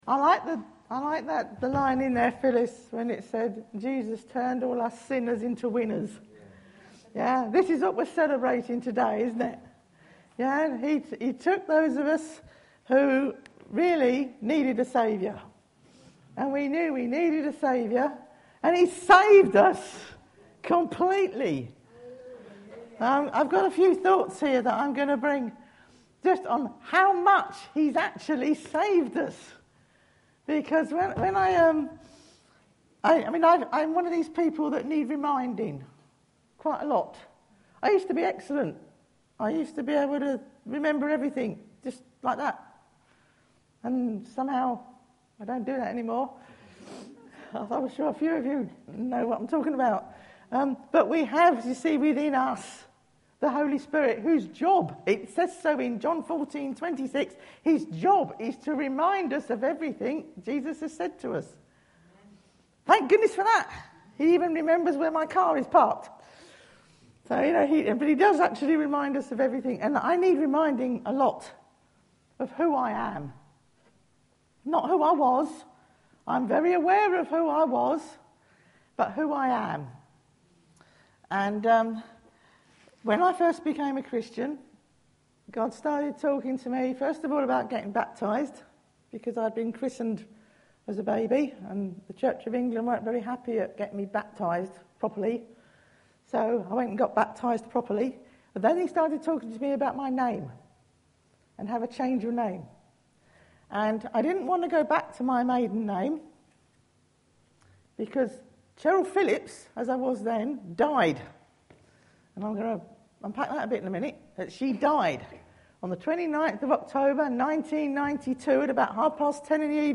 Easter Sunday
Preacher